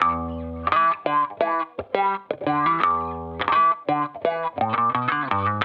Index of /musicradar/sampled-funk-soul-samples/85bpm/Guitar
SSF_StratGuitarProc1_85E.wav